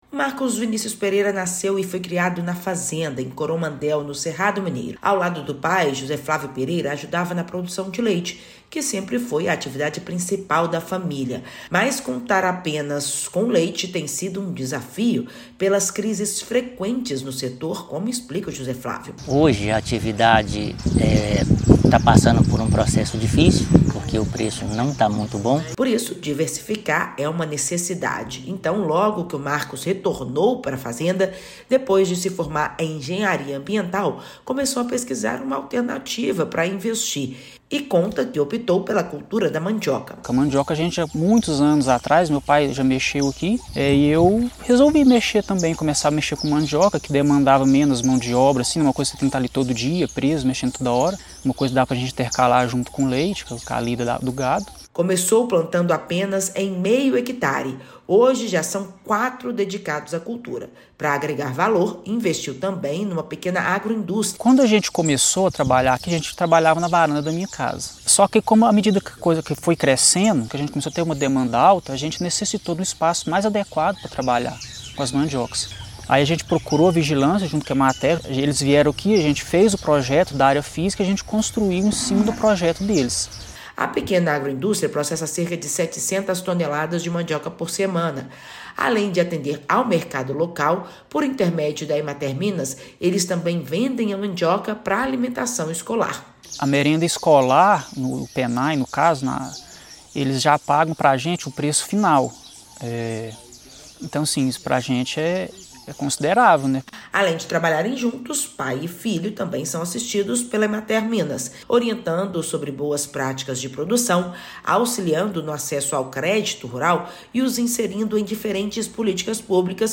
[RÁDIO] Agroindústria de mandioca é alternativa de renda para produtores de leite de Coromandel
Com assistência da Emater-MG, jovem investe na atividade para aumentar a renda. Ouça matéria de rádio.